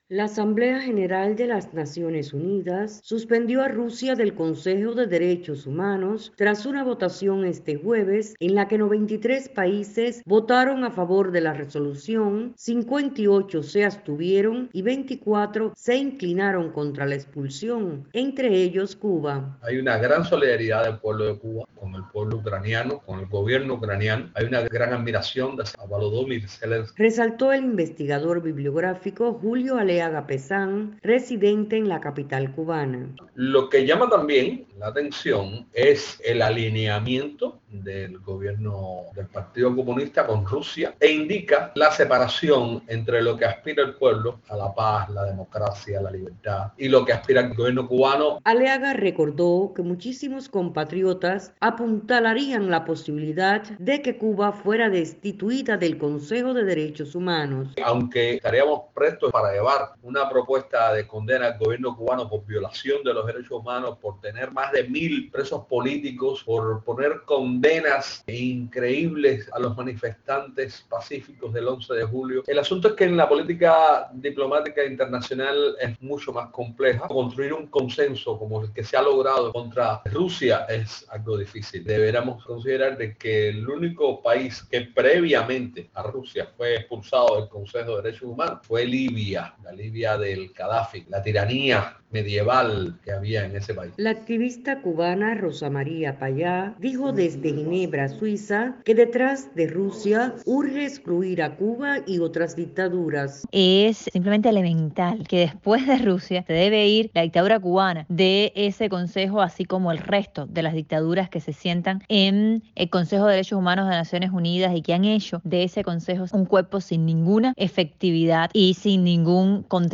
Sin embargo, la población cubana ha dado el sí a la medida, coincidieron tres entrevistados por Radio Televisión Martí.
Reportaje